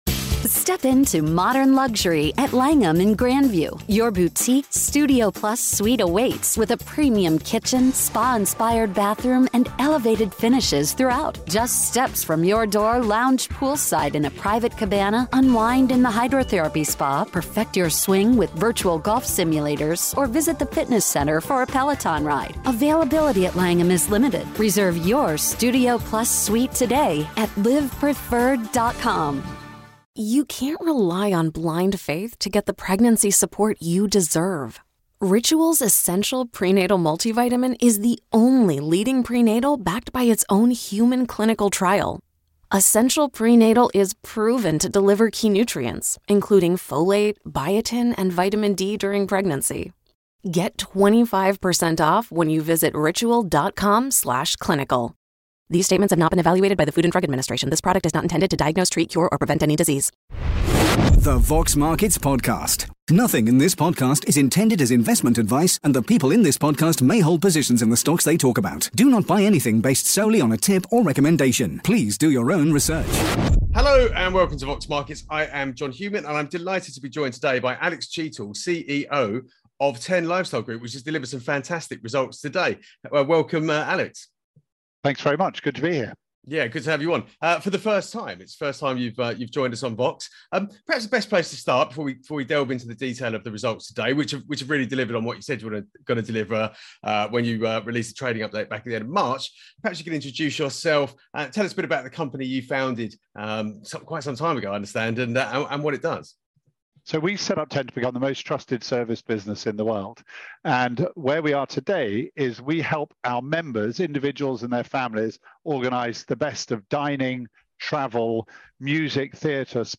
Q&A